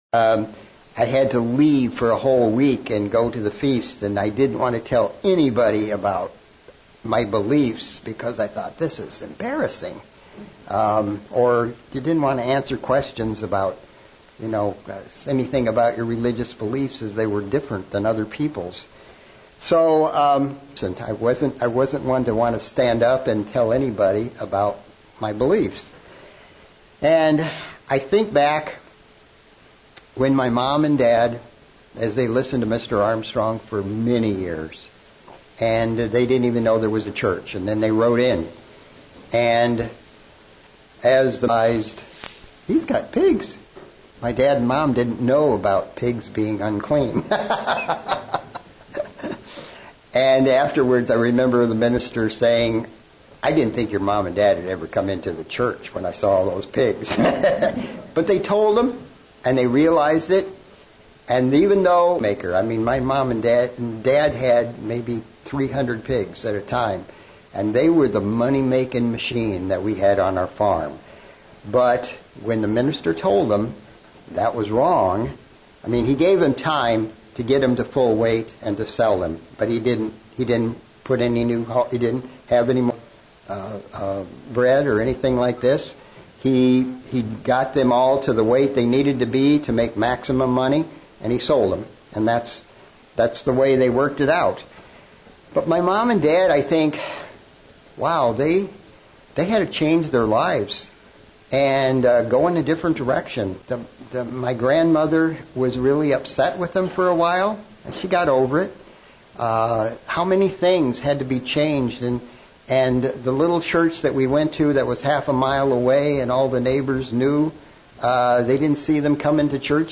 Sermon looking at the subject of humility and how we need to act as Christians. The biggest challenge to humility is prosperity, thinking we are rich and having need to nothing, while failing to realize we really need to learn to be more humble.